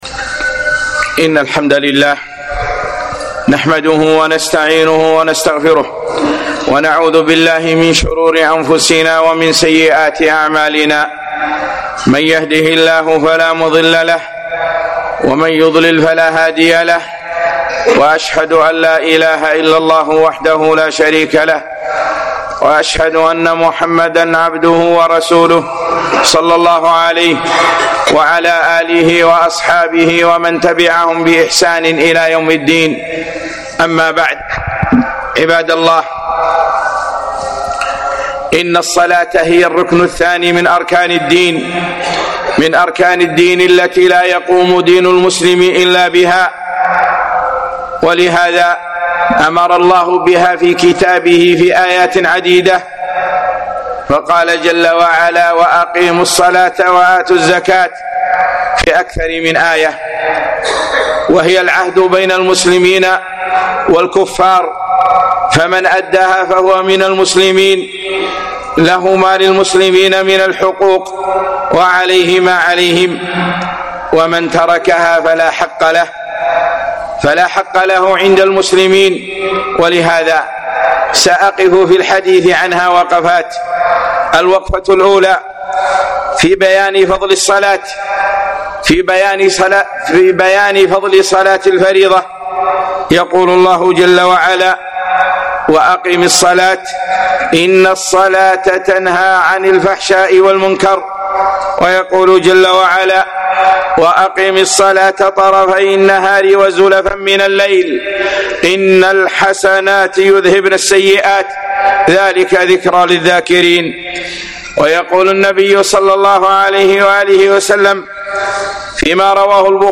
الصلاة - خطبة